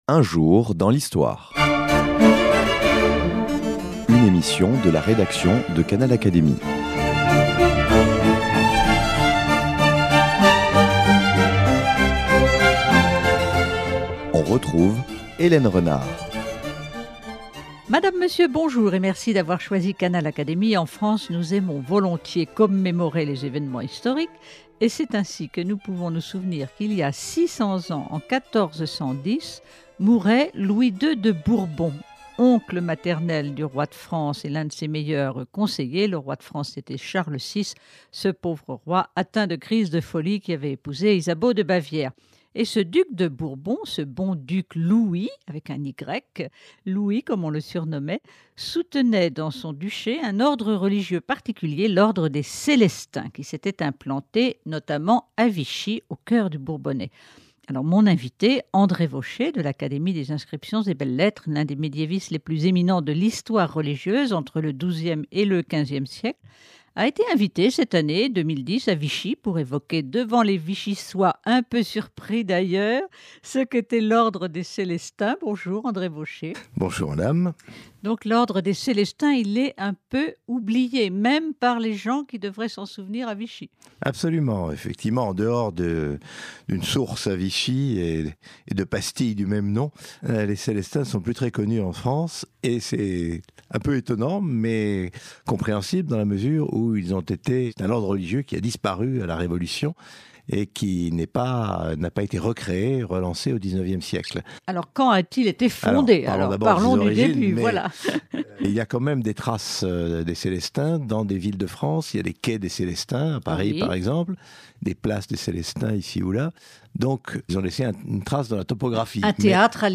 L’historien médiéviste André Vauchez, de l’Académie des inscriptions et belles-lettres, rappelle ici l’historique des Célestins, un Ordre religieux disparu, à l’occasion de la commémoration du 600e anniversaire du « Bon Duc » Louis II de Bourbon, mort en 1410.